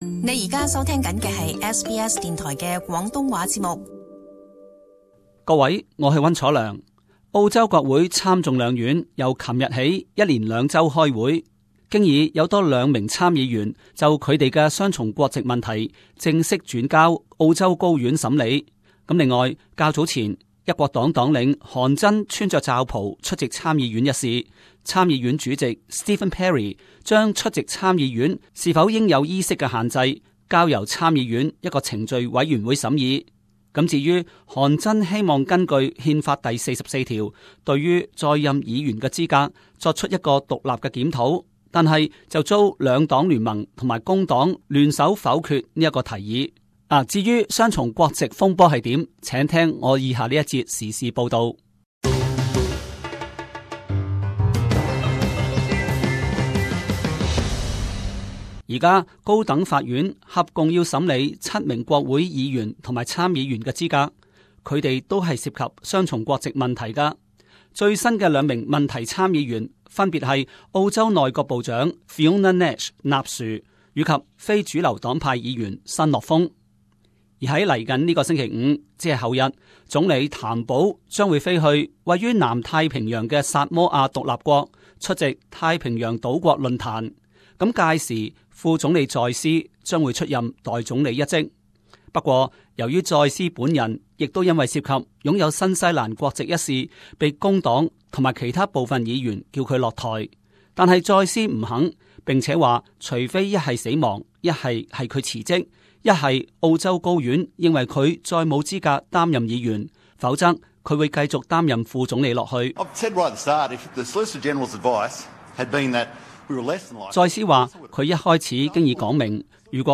【时事报导】 国会开会双重国籍问题挥之不去